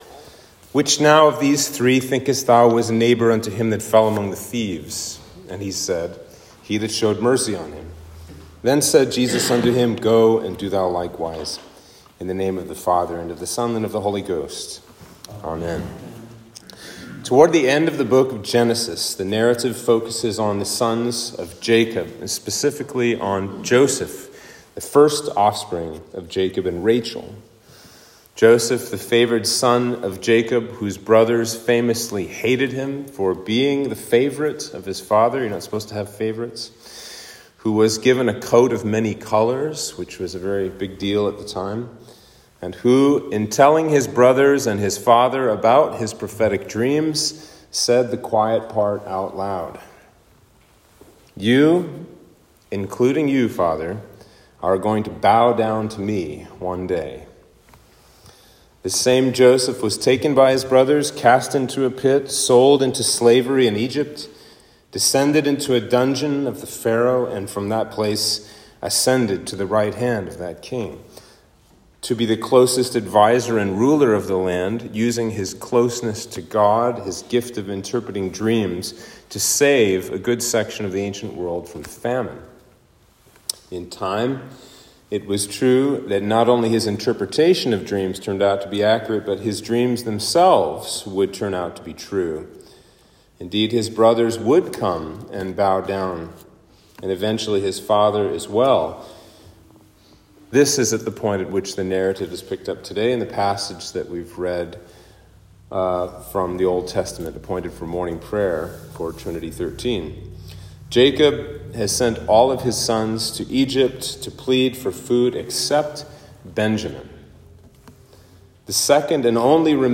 Sermon for Trinity 13